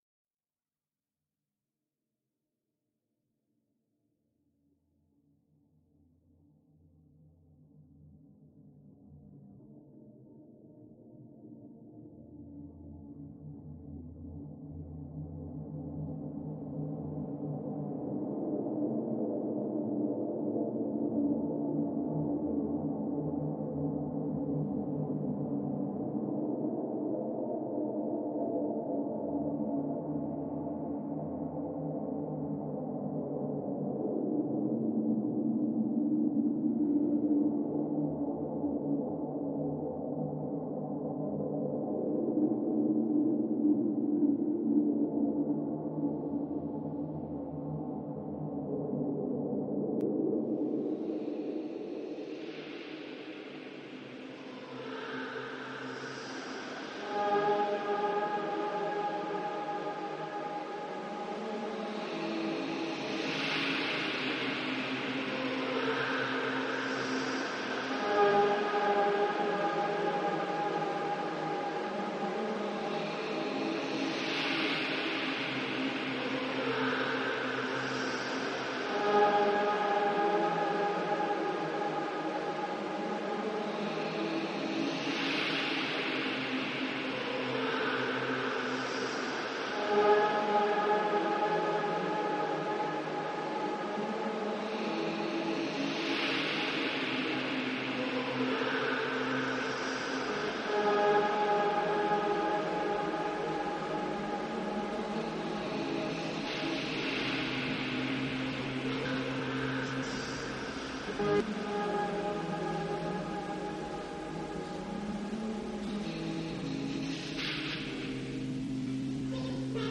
because of the texture of the warm sound of the organ, and the background sound of the people, as they walk, scuffle about.
I wanted to make something experimental and along a dark ambient path. My idea for this piece was have a possessed haunting sound compared to the original audio, the idea being that the church has been possessed by the devilish imp.
Used a lot of effects processing such as reverb and distortion, and feeding the results of the re-sampled audio using pitch shifting granular reverse echo’s and using filters to add more depth and colour to the finished sound.